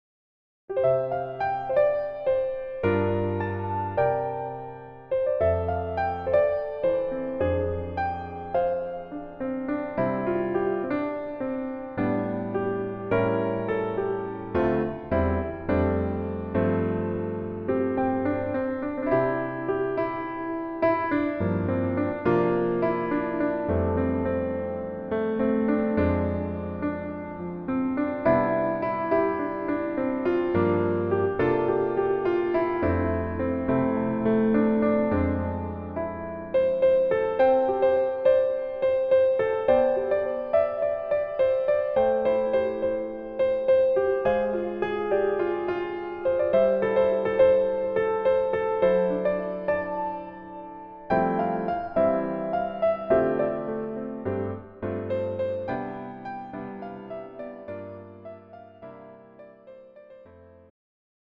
음정 -1키 4:10
장르 가요 구분 Pro MR